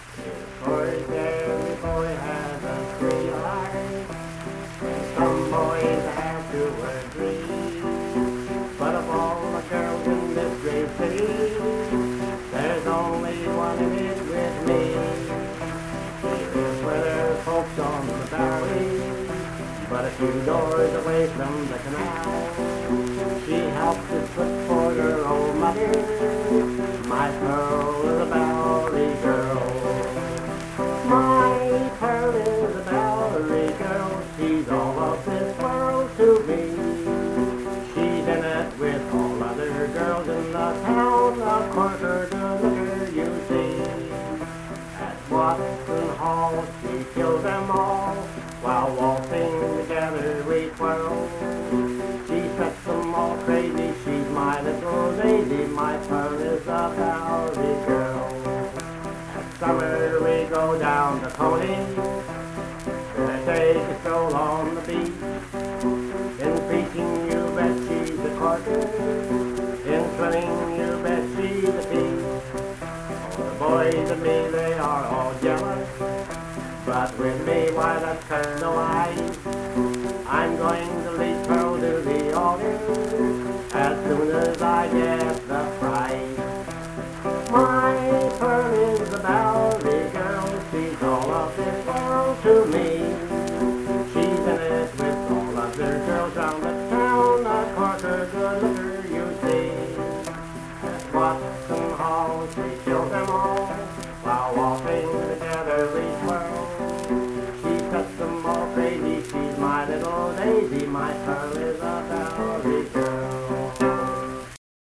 Accompanied vocal and guitar music
Performed in Hundred, Wetzel County, WV.
Voice (sung), Guitar